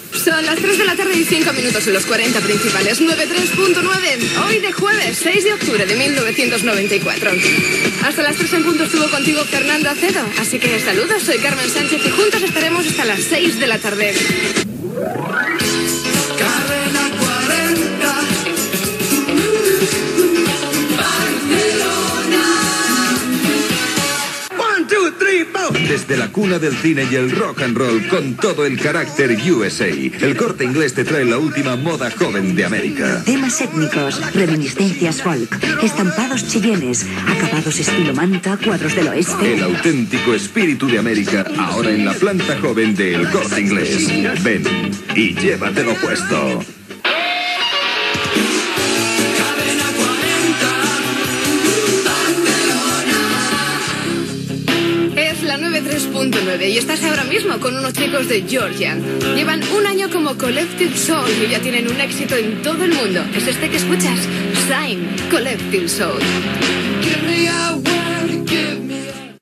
Relleu en la presentació, hora, data, indicatiu publicitat, indicatiu i tema musical.
Musical
FM